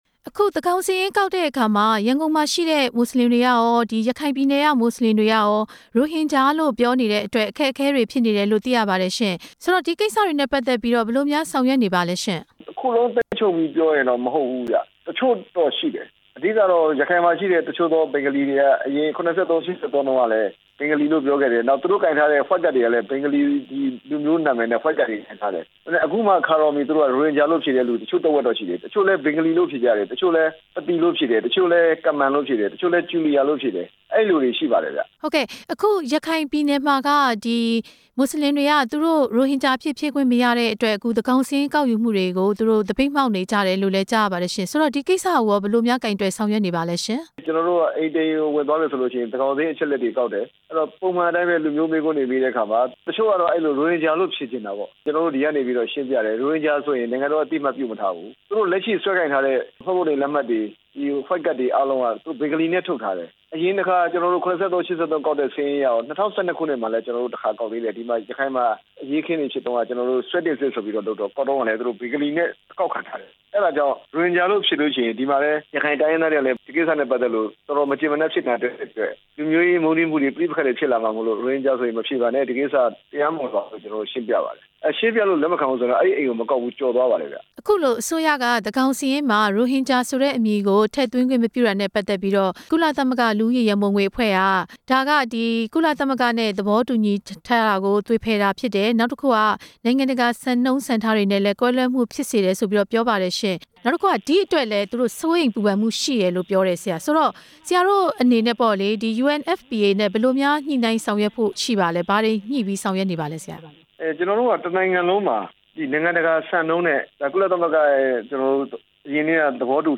သန်းခေါင်စာရင်းကောက်ယူနေမှု ညွှန်/ချုပ်နဲ့ မေးမြန်းချက်